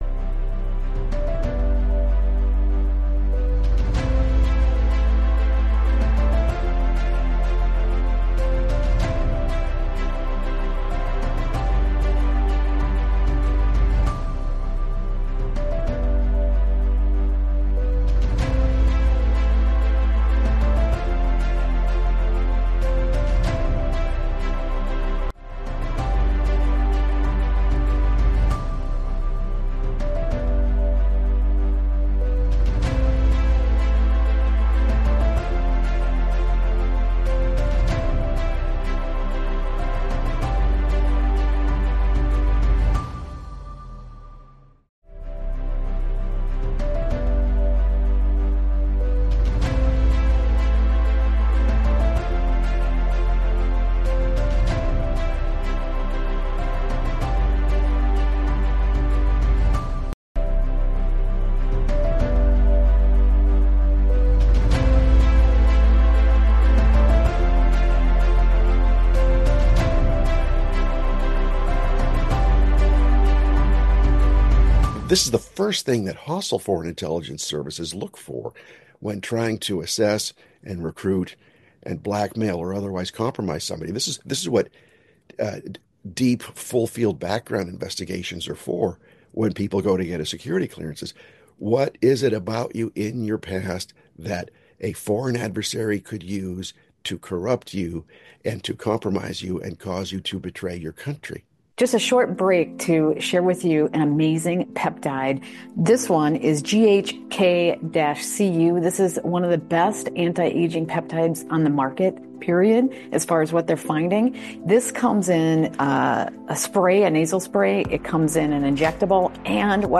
The interview also touches on the infiltration of Western values and the current state of the country.